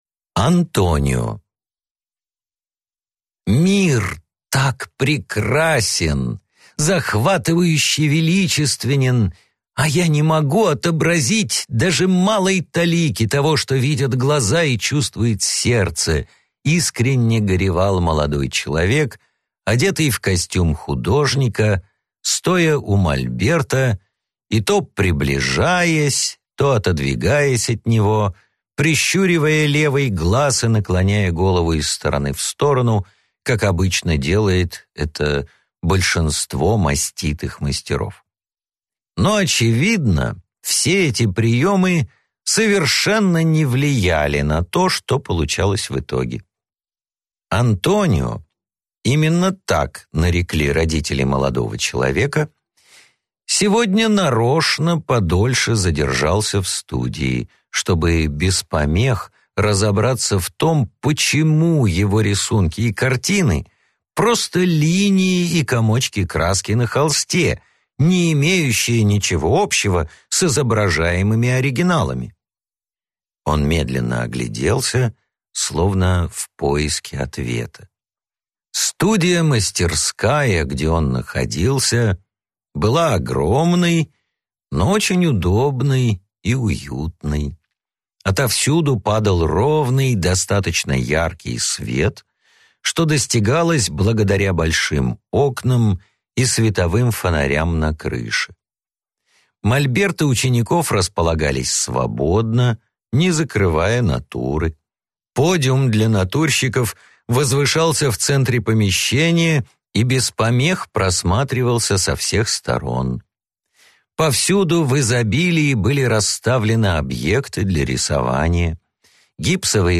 Аудиокнига Рассказки | Библиотека аудиокниг